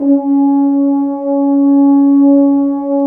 Index of /90_sSampleCDs/Roland LCDP06 Brass Sections/BRS_F.Horns 2 mf/BRS_FHns Dry mf